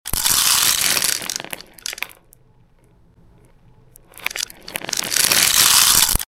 Satisfying With Unboxing Miniature Toys sound effects free download
Satisfying With Unboxing Miniature Toys ASMR VIDEOS